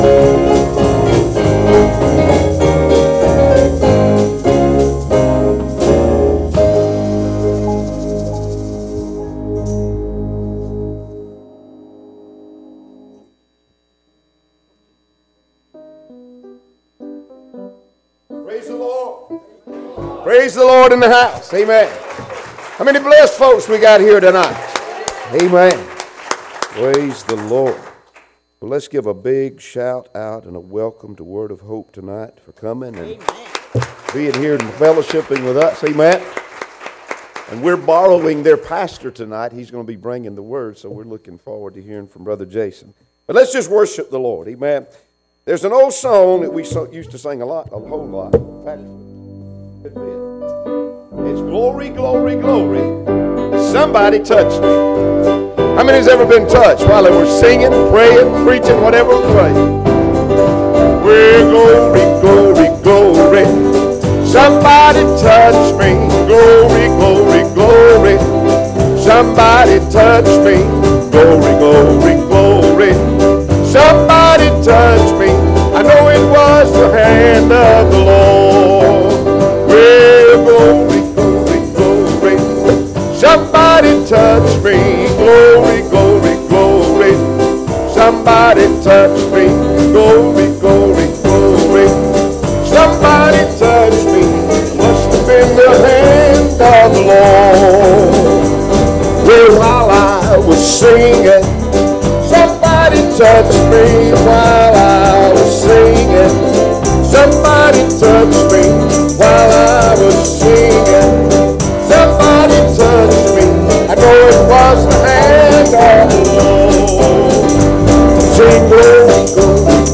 Service Type: Sunday Evening Services